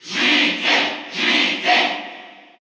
File:Villager Male Cheer Russian SSBU.ogg
Crowd cheers (SSBU) You cannot overwrite this file.
Villager_Male_Cheer_Russian_SSBU.ogg